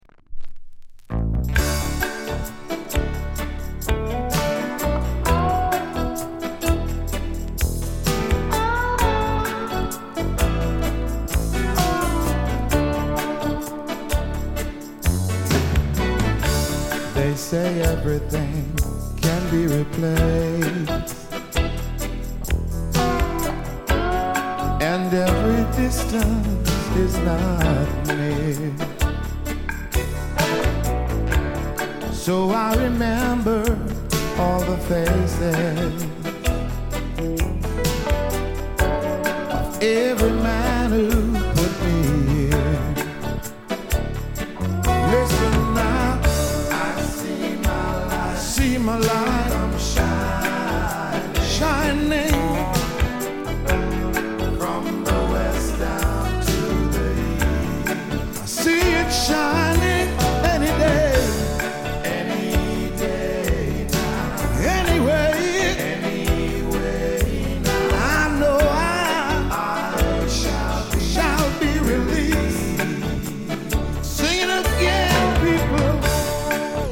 SOUL作品